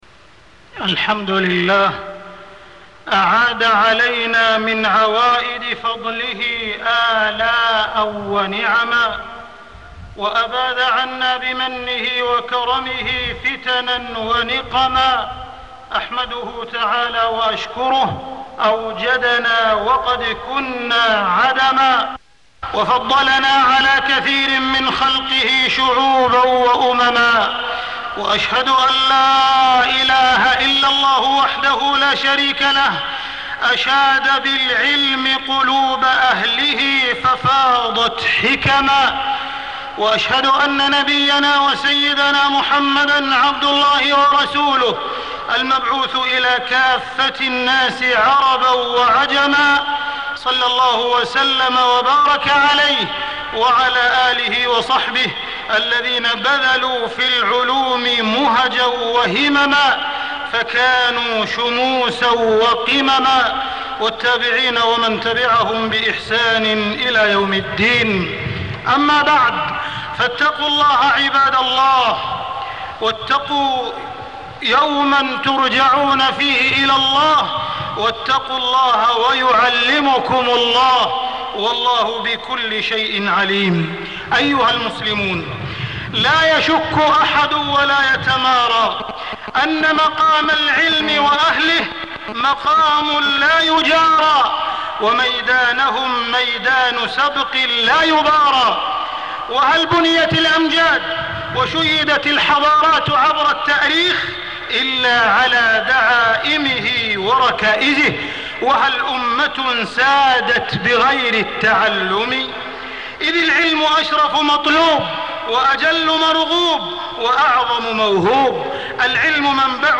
تاريخ النشر ١٢ شعبان ١٤٢٦ هـ المكان: المسجد الحرام الشيخ: معالي الشيخ أ.د. عبدالرحمن بن عبدالعزيز السديس معالي الشيخ أ.د. عبدالرحمن بن عبدالعزيز السديس كلمات للمعلمين والمعلمات